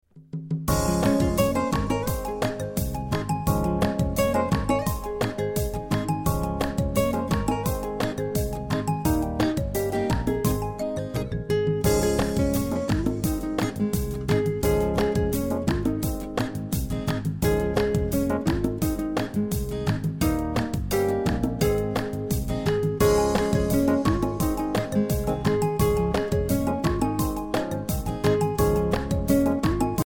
Listen to a sample of the instrumental version.